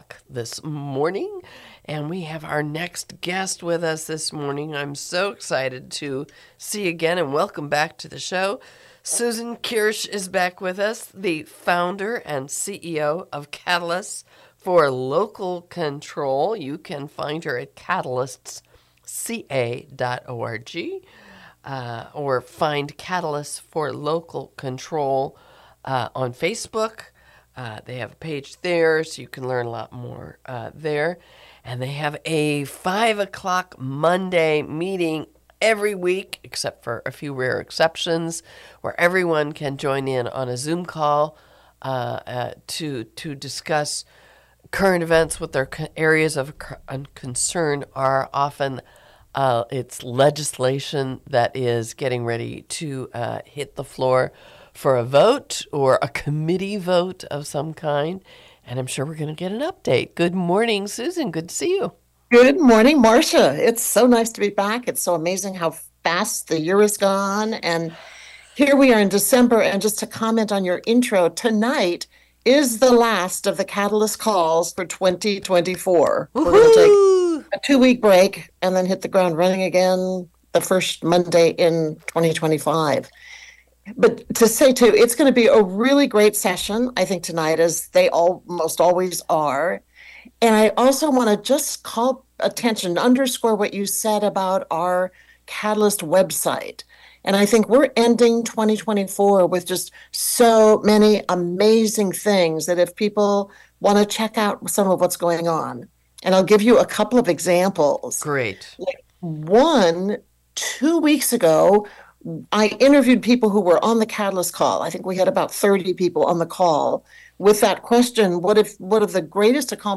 Here is the interview for April 21, 2025